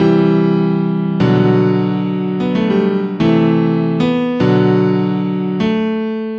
有意义的钢琴和弦
Tag: 150 bpm Trap Loops Piano Loops 1.08 MB wav Key : F